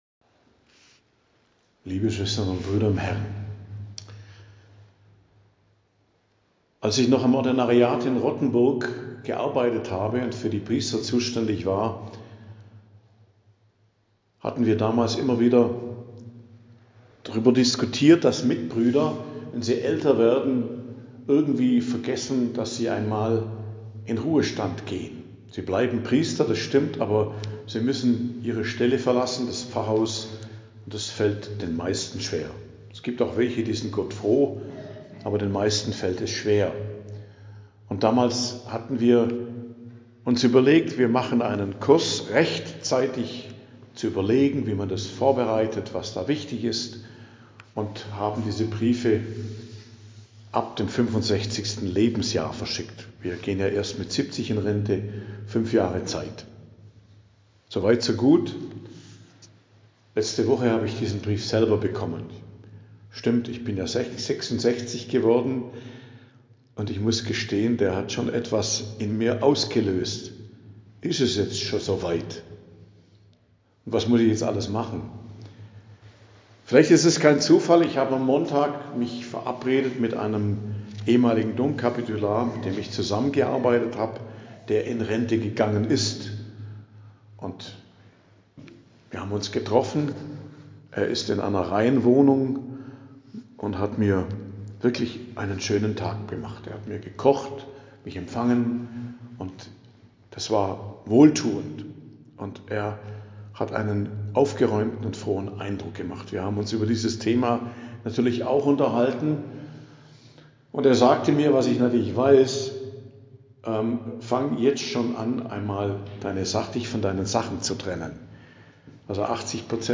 Predigt am Donnerstag der 4. Woche i.J. 6.02.2025